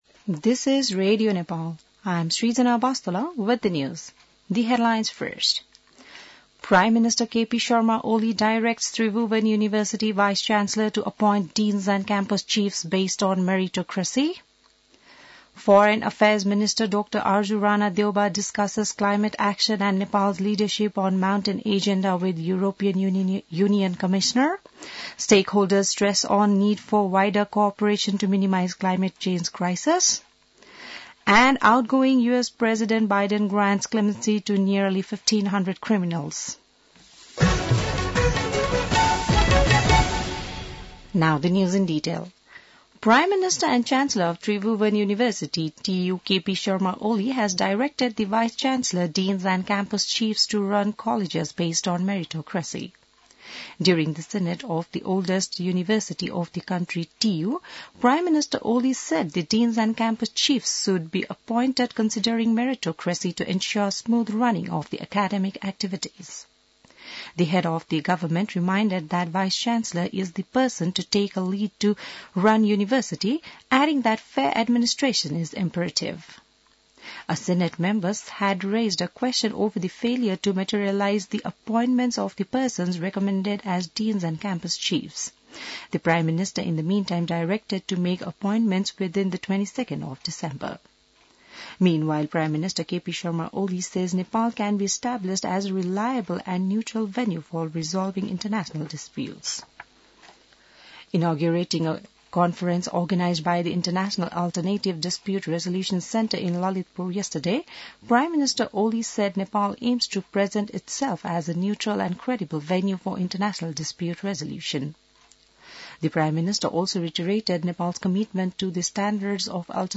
बिहान ८ बजेको अङ्ग्रेजी समाचार : २९ मंसिर , २०८१